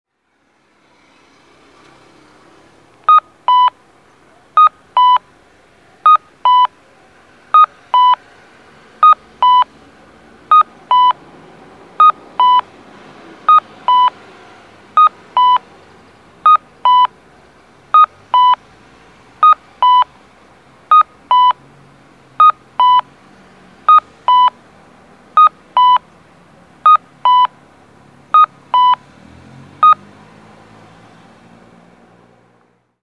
交通信号オンライン｜音響信号を録る旅｜京都府の音響信号｜[0435]クニッテル春日坂
クニッテル春日坂(京都府亀岡市)の音響信号を紹介しています。